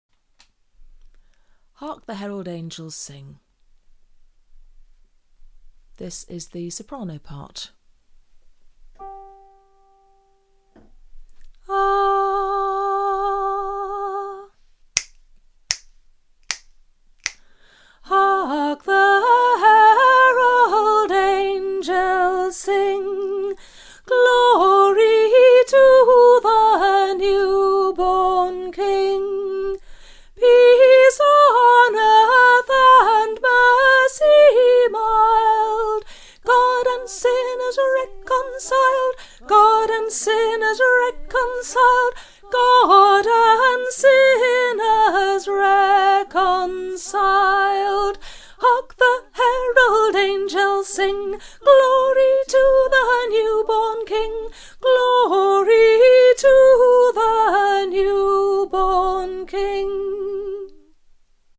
Traditional 'village' carols in pubs
Carlingcott_Sop.mp3